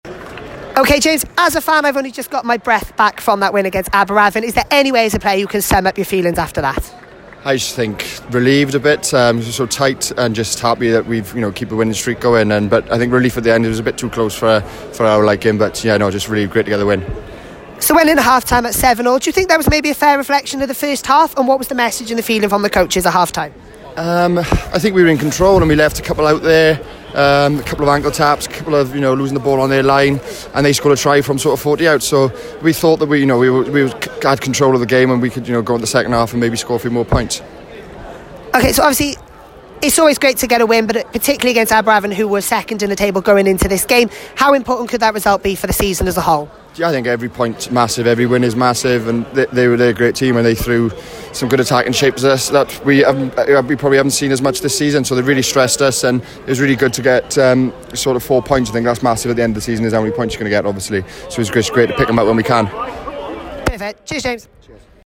Post Match Interviews.